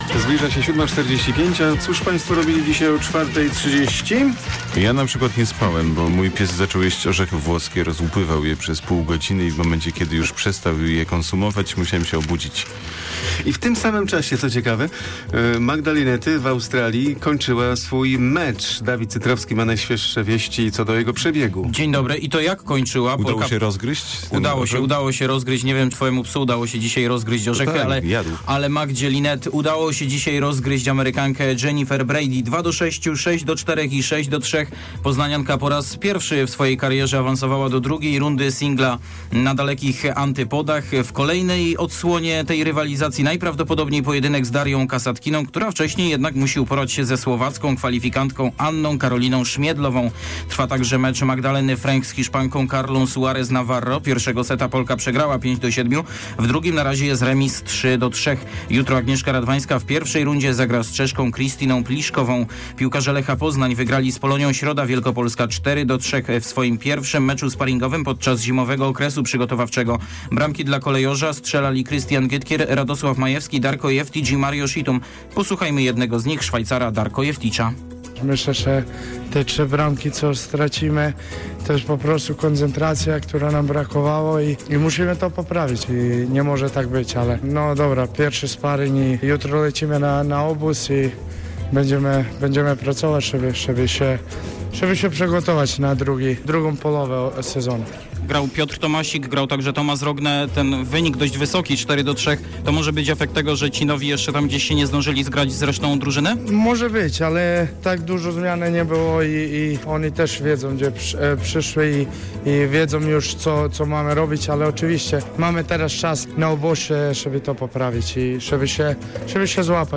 15.01 serwis sportowy godz. 7:45